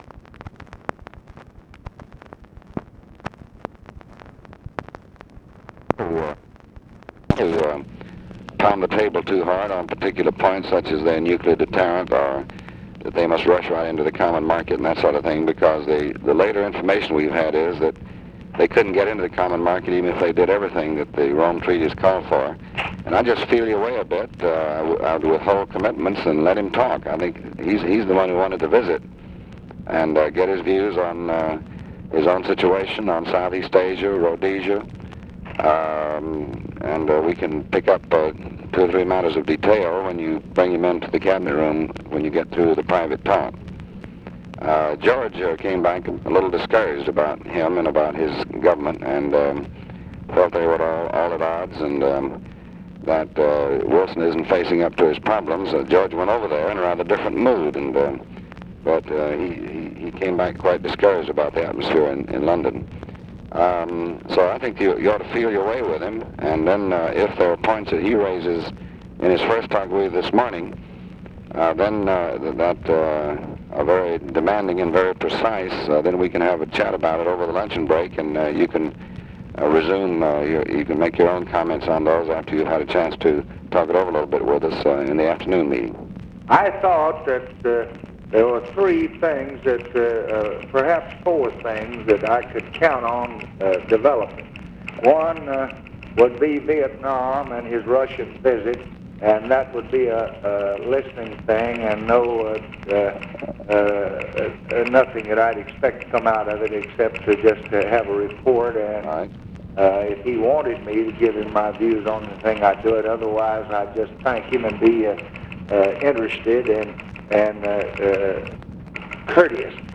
Conversation with DEAN RUSK, July 29, 1966
Secret White House Tapes